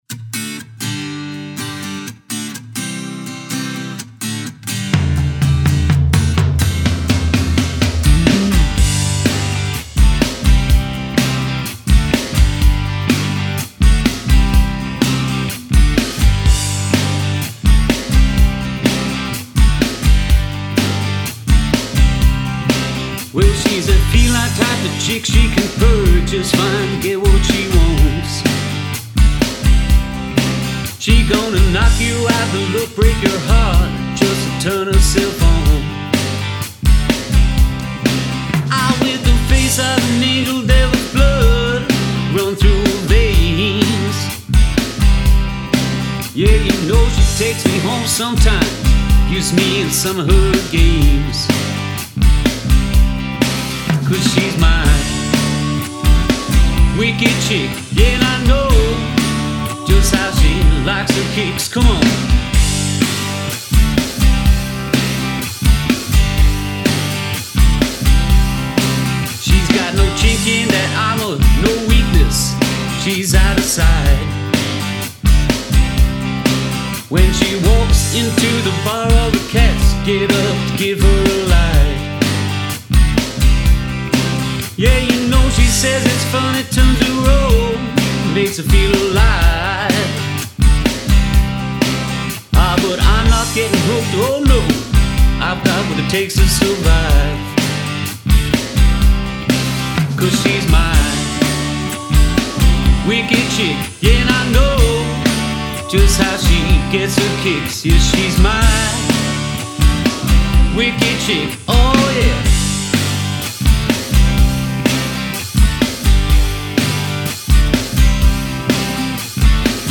Still waiting for the lead guitarist to put down his parts on it. Everything was done in my bedroom really. The drummer used an electric kit and I used that as a reference for BFD. Guitars are using Helix native. I can only mix on headphones really, so I'd love to hear some feedback on it.